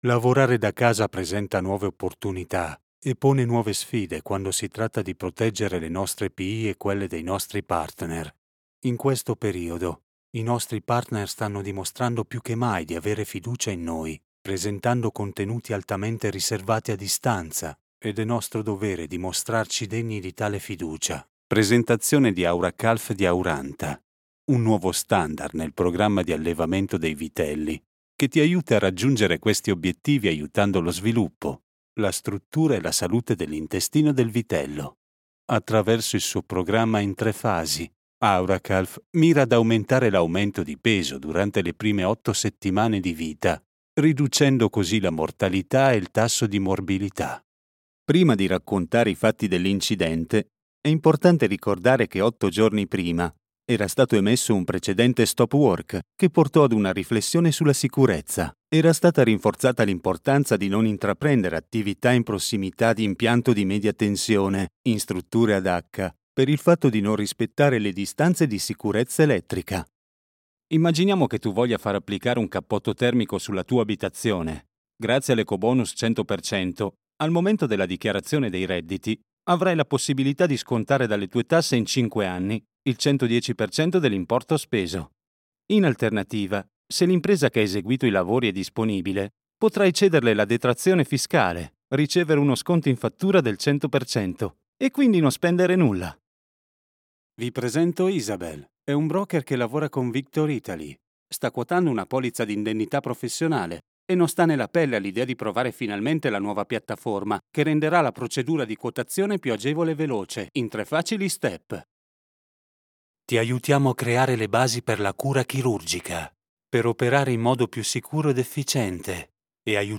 Male
30s, 40s
Cool, Corporate, Deep, Natural, Warm, Authoritative, Character
ITALIAN STANDARD / NORTHERN / SOUTHERN
Audiobooks & Narrations Reel.mp3
Microphone: SONTRONICS
Audio equipment: APOGEE 2 / CONNECTION BY SKYPE